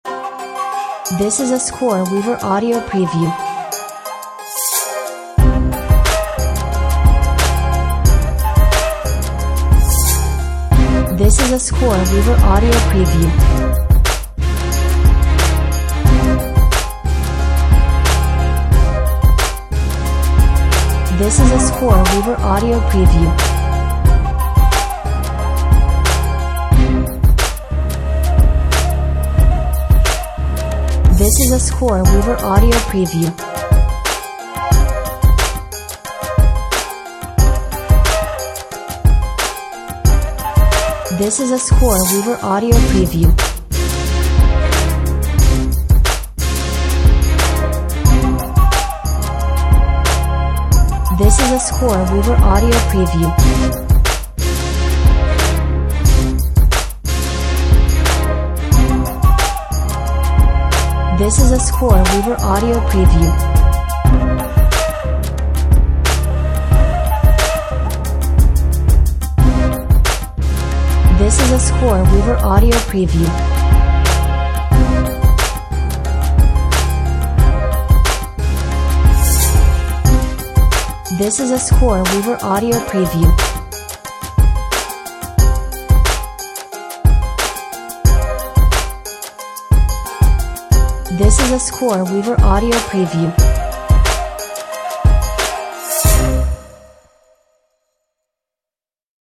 Bold and proud Hip Hop with oriental influences.